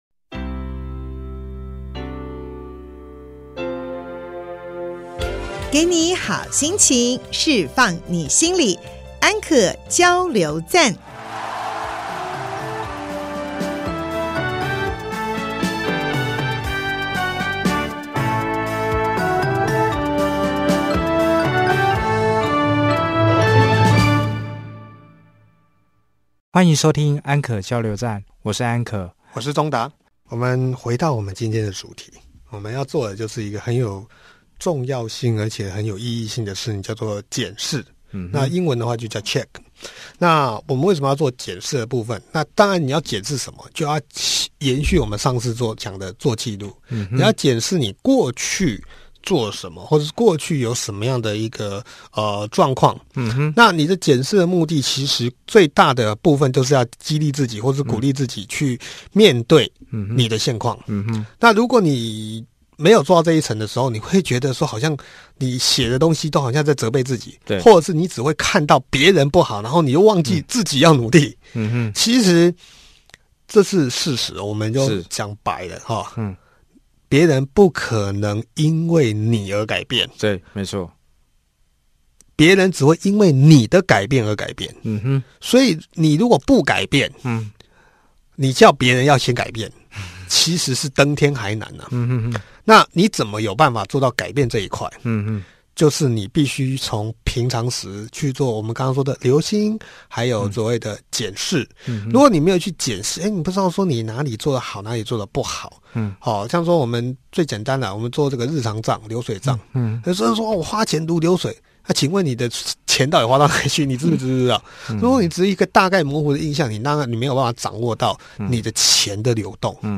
節目裡有生活點滴的分享、各界專業人物的心靈層面探析及人物專訪，比傳統心理節目多加了歷史人物與音樂知識穿插，更為生動有趣，陪伴您度過深夜時光，帶來一週飽滿的智慧與正能量。